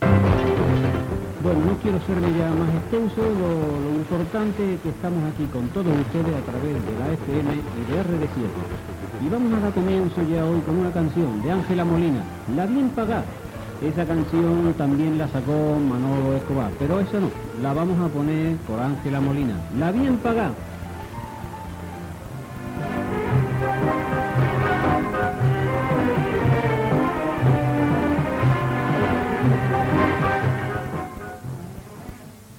Identificació de l'emissora i tema musical.
Musical
FM